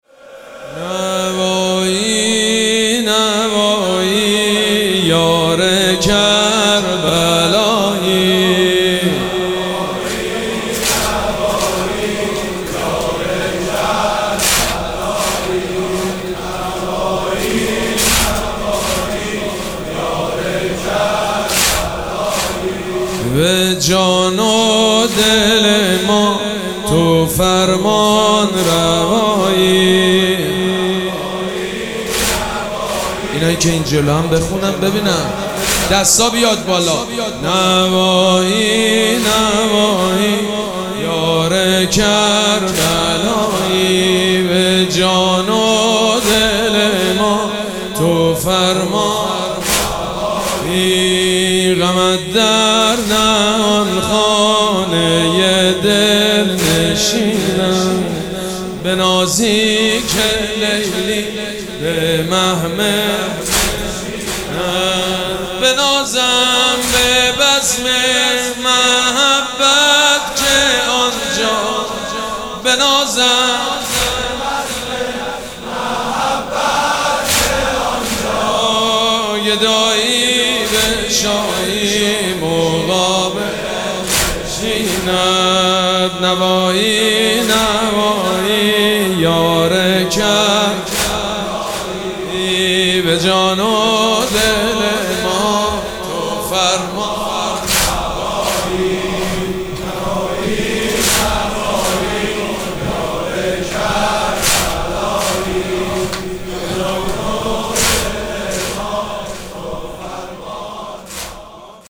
مراسم عزاداری شب سوم محرم الحرام ۱۴۴۷
مداح
حاج سید مجید بنی فاطمه